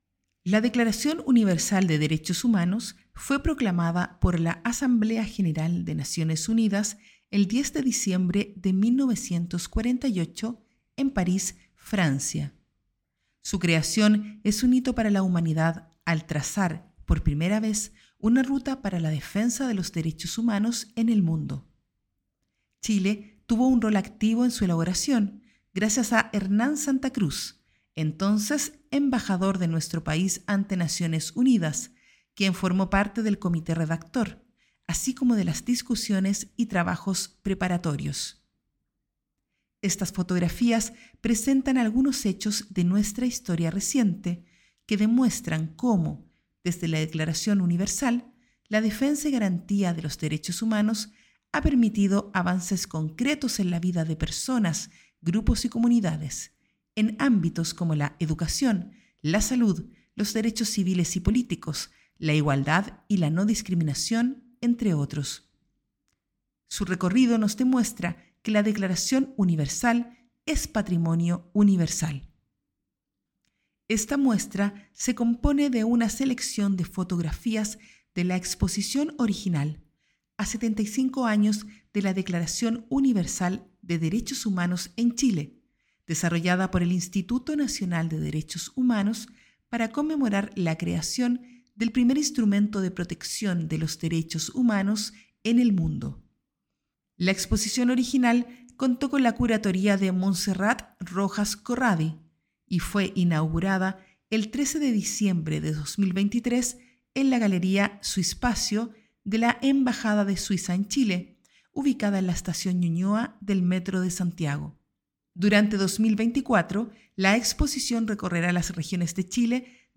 Audiotexto
exposicion-A-75-anos-de-la-Declaracion-Universal-texto-curatorial.mp3